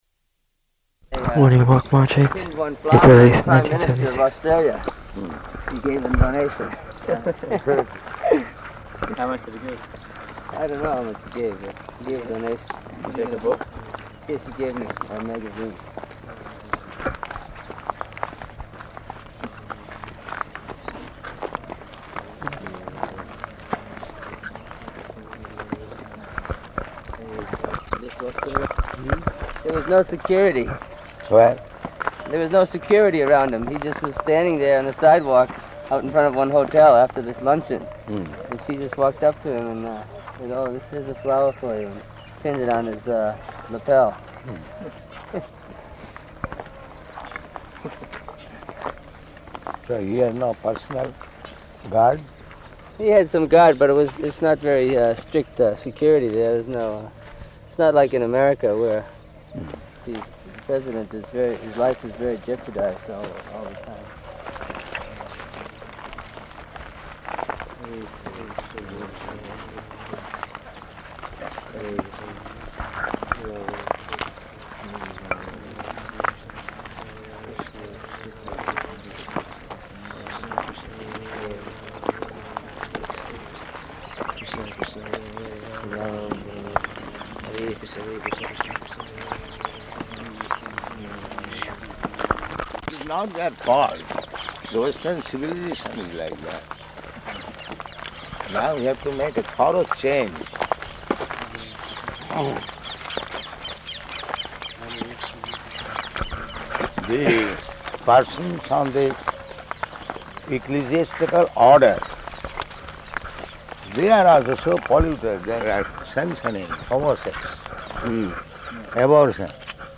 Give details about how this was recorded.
Morning Walk --:-- --:-- Type: Walk Dated: April 8th 1976 Location: Vṛndāvana Audio file: 760408MW.VRN.mp3 Devotee: [announces:] Morning walk March 8th, [indistinct] 1976.